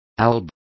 Complete with pronunciation of the translation of albs.